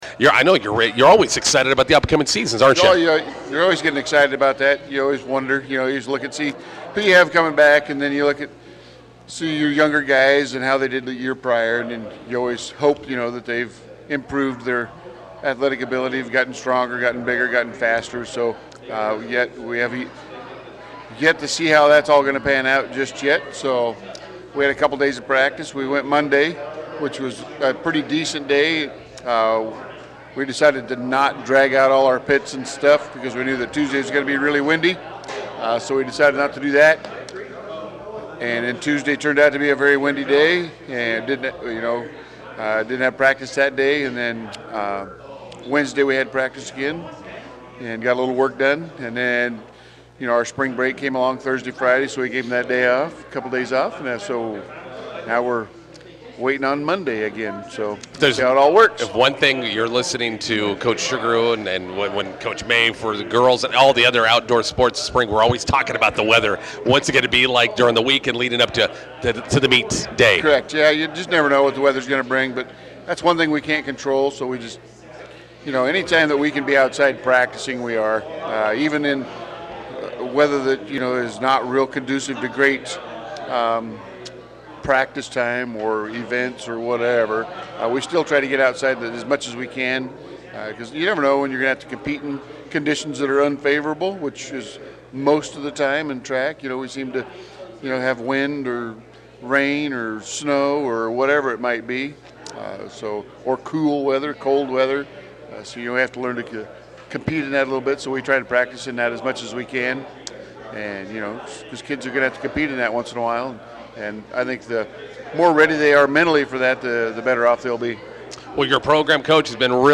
INTERVIEW: Bison Track and Field teams open 2025 spring season today at UNK Indoor.